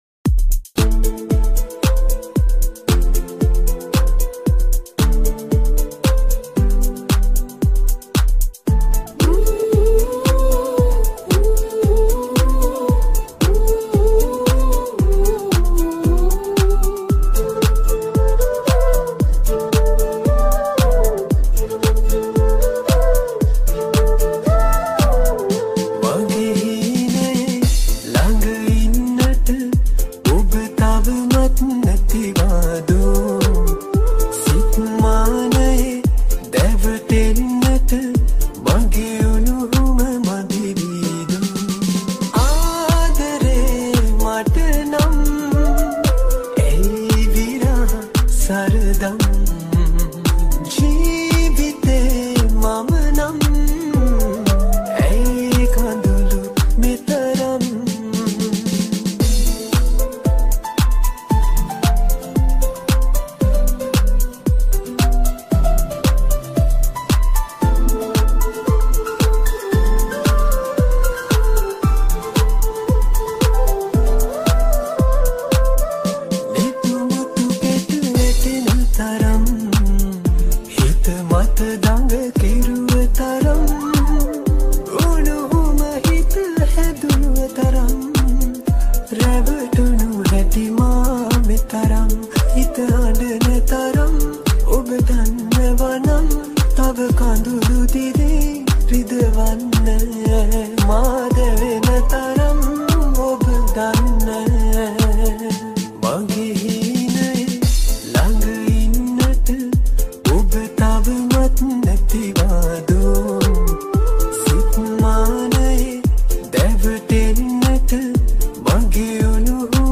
High quality Sri Lankan remix MP3 (3.4).